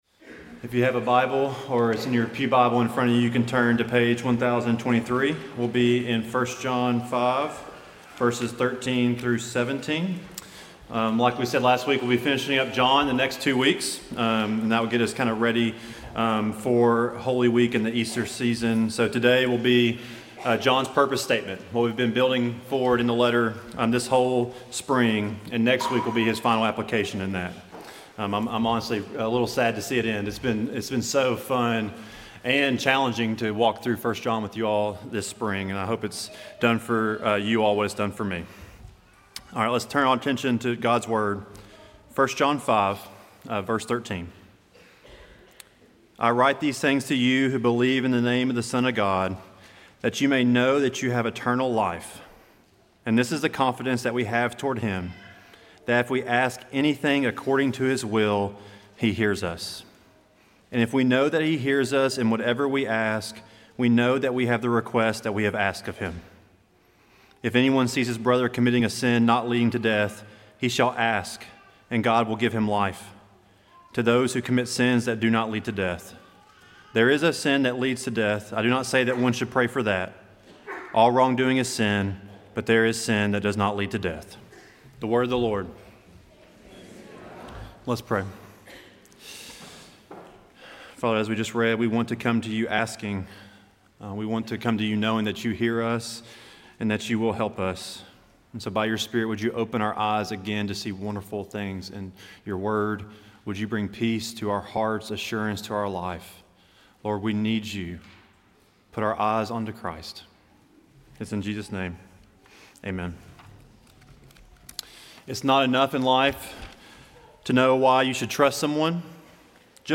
Sermons recorded during worship at Tates Creek Presbyterian Church (PCA) in Lexington, KY